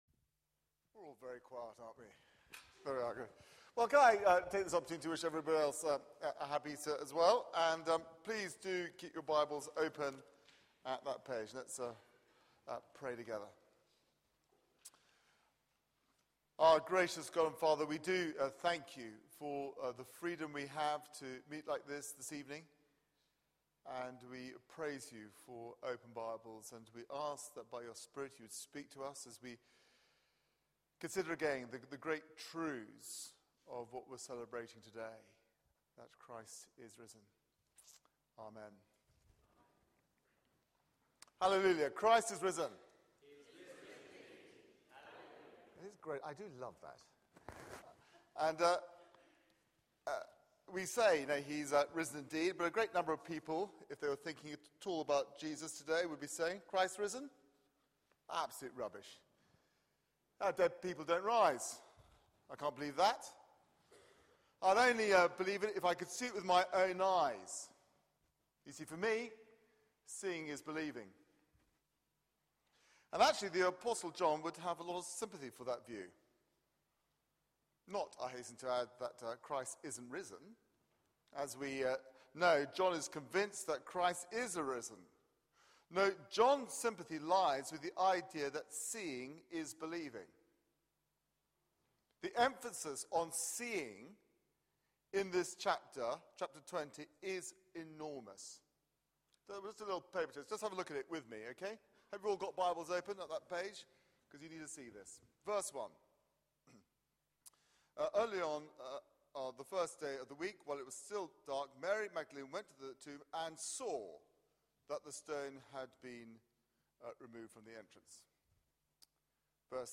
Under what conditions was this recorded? Media for 6:30pm Service on Sun 31st Mar 2013 18:30 Speaker